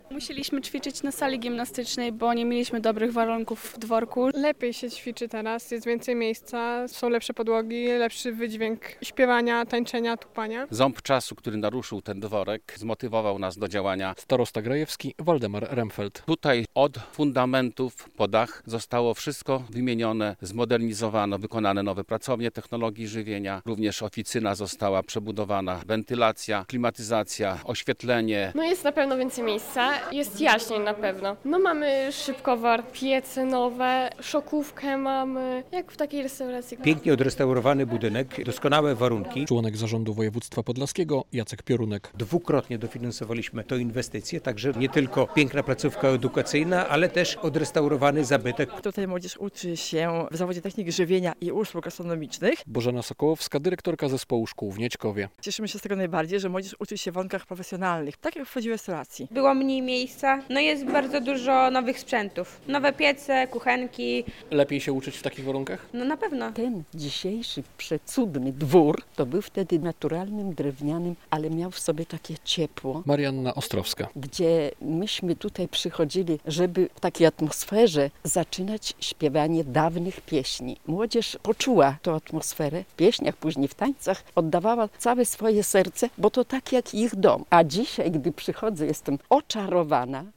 relacja
Remont dworku był konieczny, bo widać już było "ząb czasu" - mówił starosta grajewski Waldemar Remfeld.